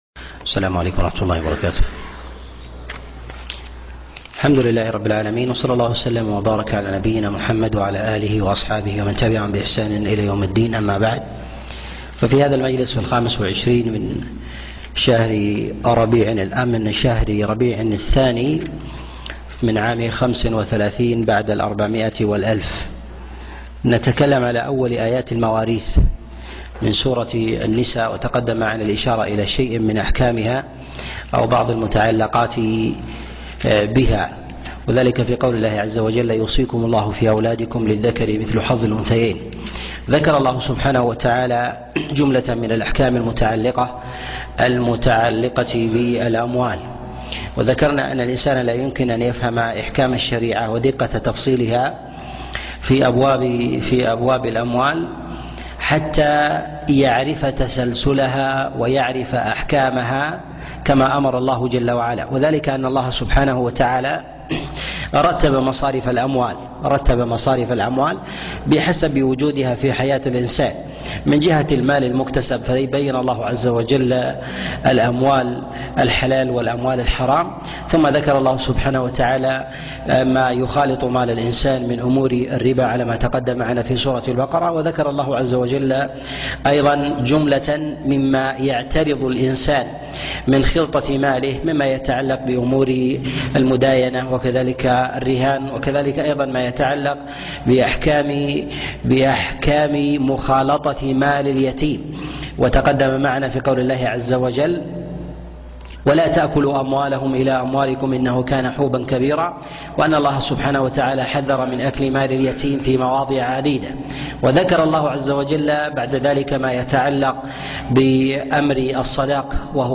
تفسير سورة النساء 5 - تفسير آيات الأحكام - الدرس التاسع والخمسون